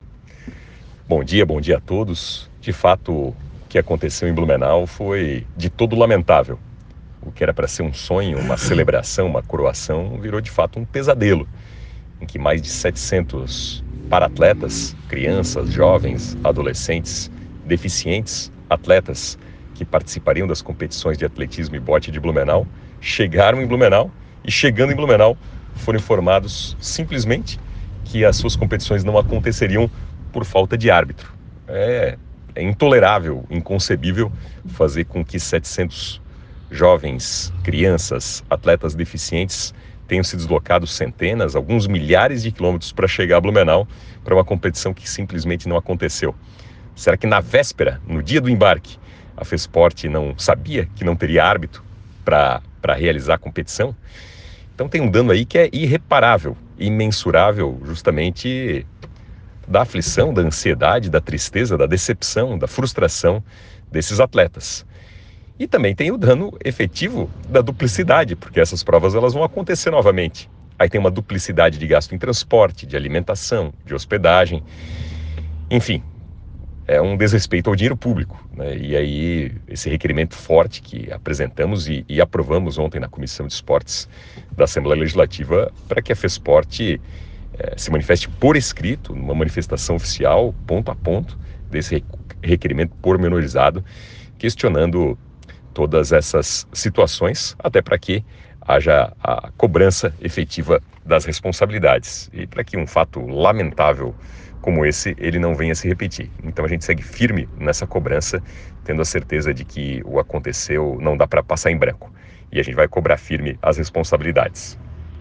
Áudio do deputado Napoleão Bernardes